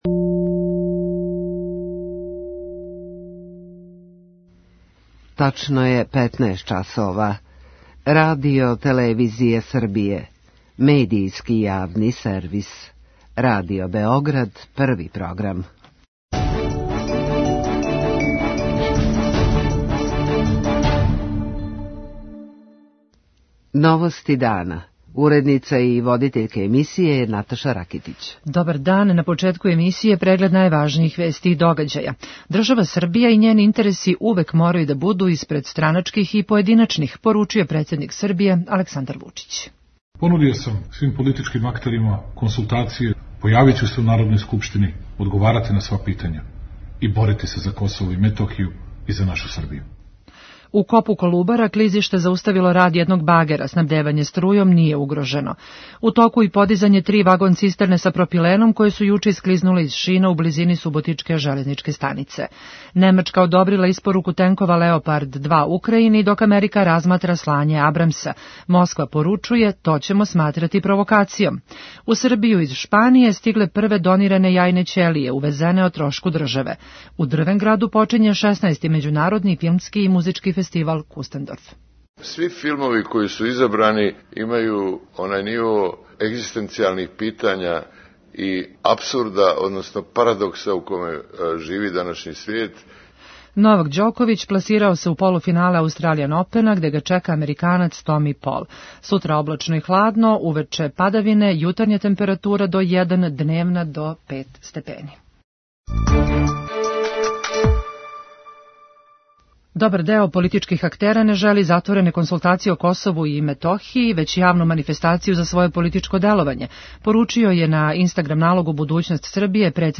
Председник је оценио да поједине странке користе тешку ситуацију како би оствариле политичку корист. преузми : 6.26 MB Новости дана Autor: Радио Београд 1 “Новости дана”, централна информативна емисија Првог програма Радио Београда емитује се од јесени 1958. године.